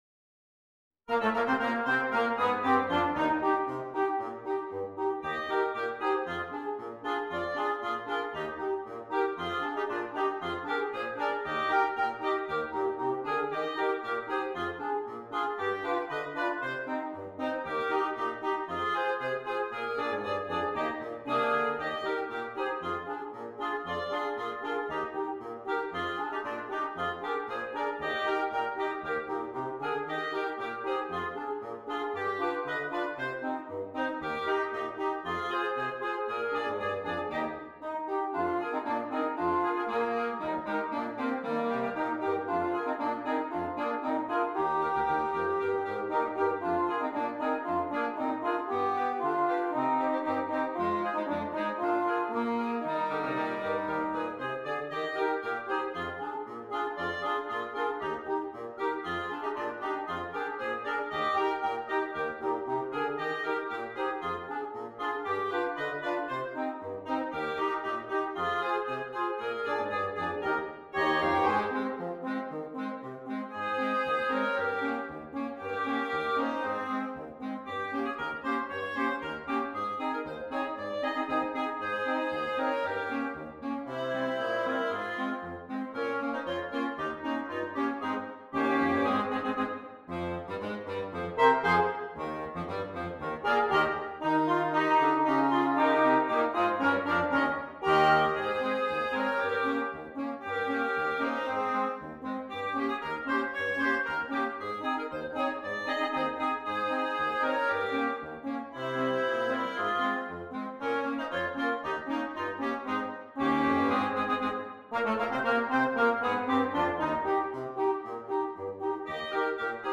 Interchangeable Woodwind Ensemble
German-Czech style polkas